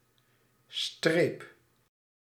Ääntäminen
France: IPA: [tʁɛ]